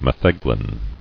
[me·theg·lin]